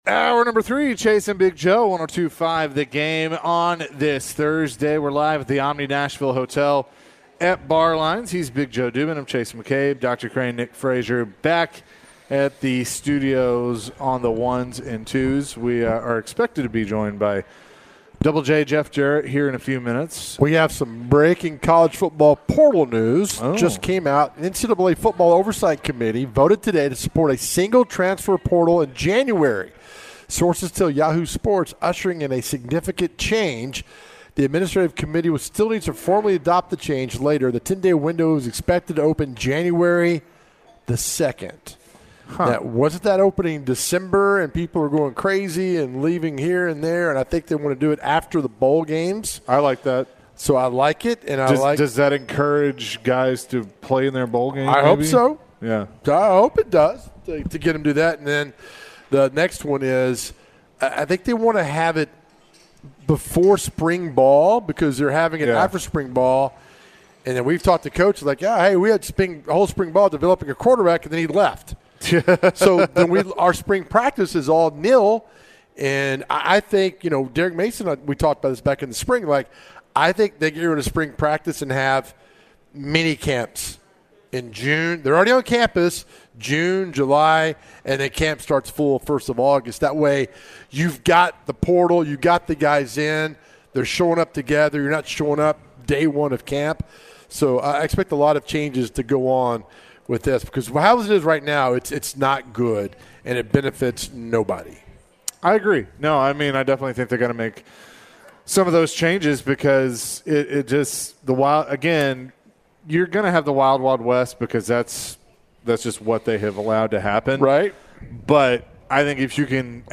In the final hour of the show, the NCAA voted on a transfer portal window. Later in the hour, AEW Wrestler Jeff Jarrett joined the show. Jeff went down memory lane and shared his stories of the wrestling business.